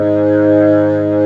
55O-ORG03-G#.wav